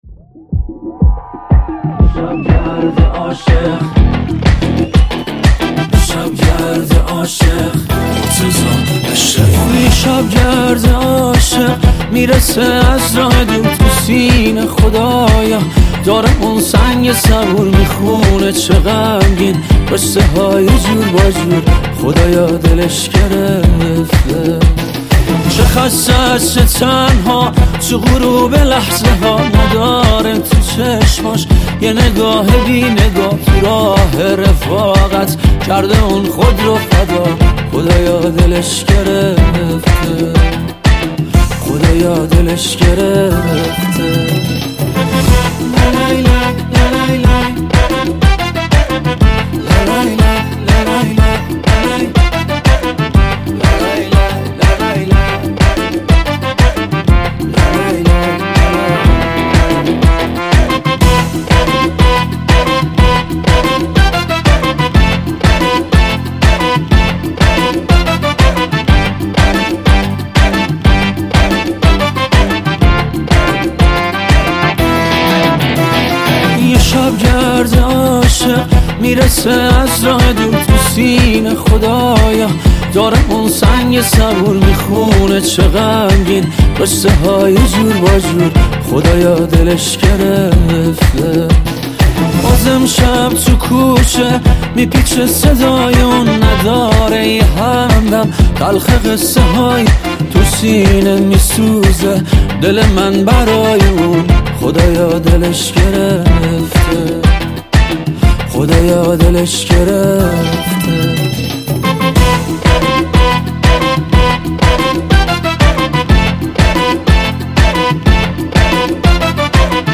موزیک شاد